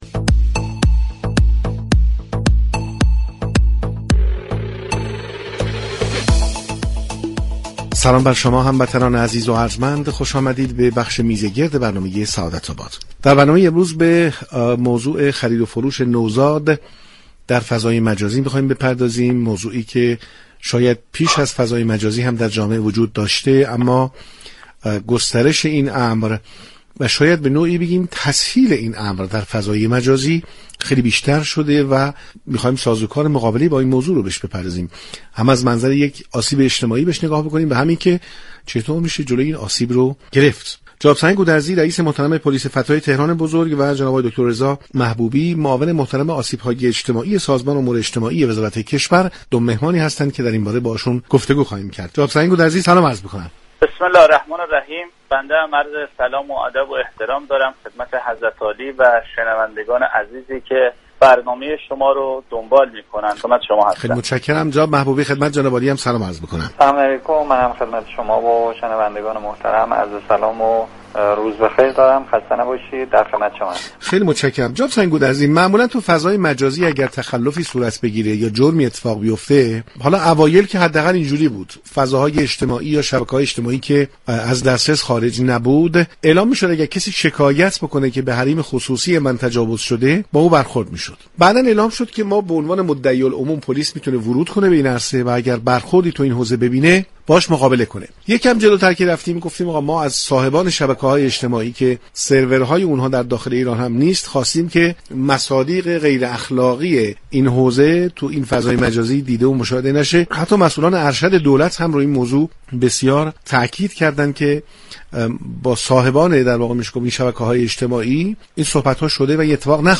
برنامه سعادت آباد سه شنبه 11 آبان ماه با حضور رئیس پلیس فتای تهران بزرگ و معاون آسیب های اجتماعی سازمان امور اجتماعی وزارت كشور با موضوع فروش نوزادان در فضای مجازی و اقدامات پیشگیرانه پلیس فتا و وزارت كشور از رادیو تهران پخش شد.